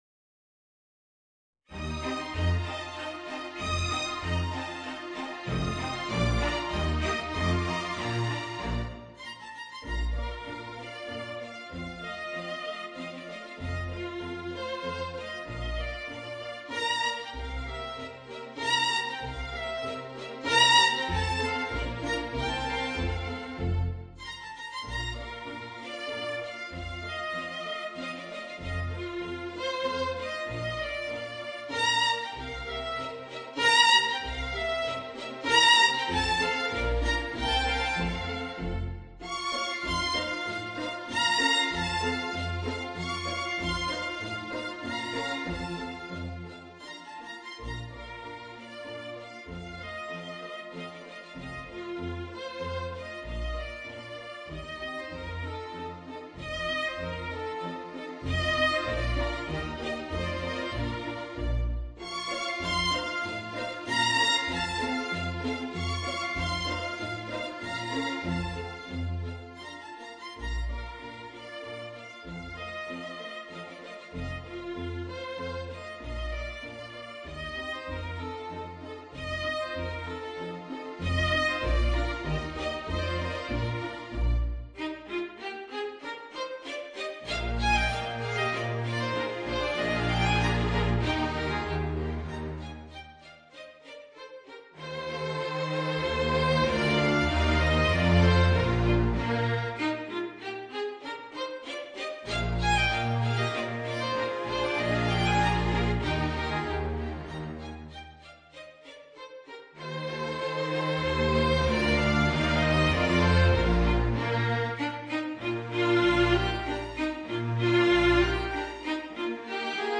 Voicing: Clarinet and String Orchestra